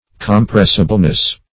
Search Result for " compressibleness" : The Collaborative International Dictionary of English v.0.48: Compressibleness \Com*press"ible*ness\, n. The quality of being compressible; compressibility.
compressibleness.mp3